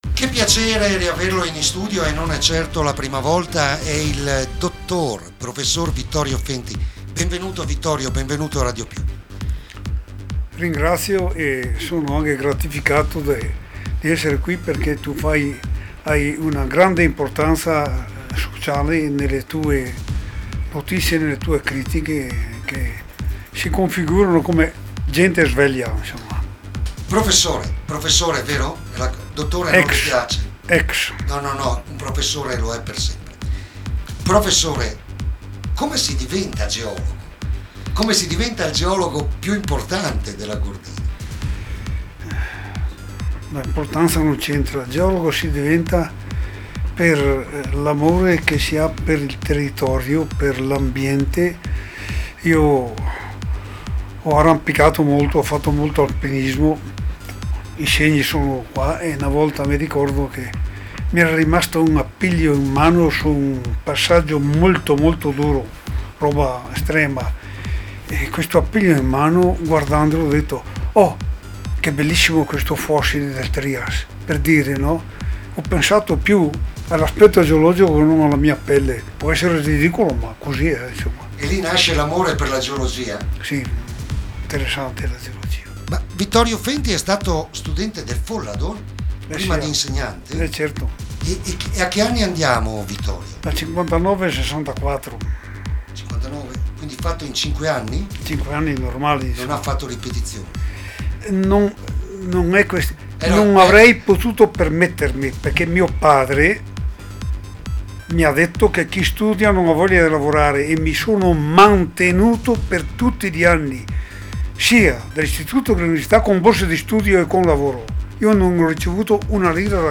ALLA RADIO